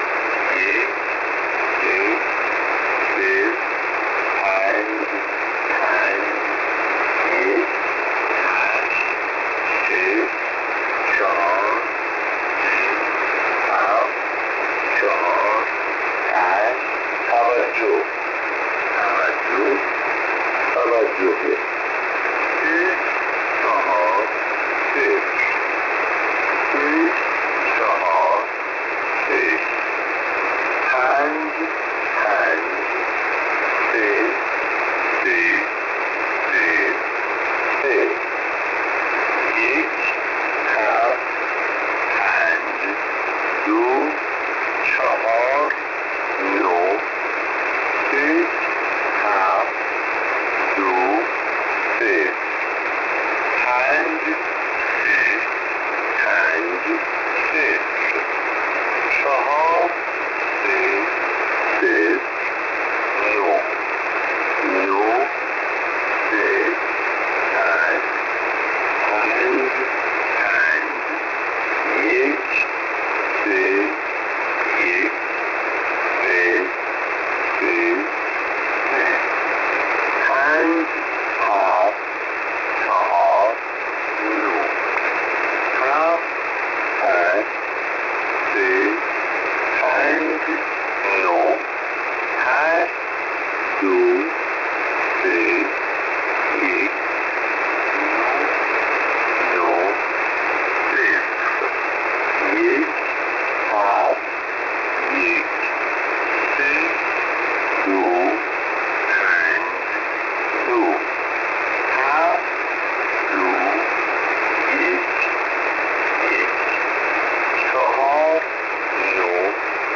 It has been confirmed by Persian (Farsi) speakers that the signal indeed broadcasts strings of numbers in Persian language.
The V32 transmission opens with a male voice — possibly synthetic or pre-recorded — saying “Tavajoh! Tavajoh! meaning Attention! Attention!”
The jammer noise sounded like bubbles, which resembles the “bubble jammer” that has been reported to be used by Islamic Republic Iran for years.
Recording using Cyprus based remote KiwiSDR
Emission mode: USB
Voice Summary: Male automated voice in Persian (Farsi)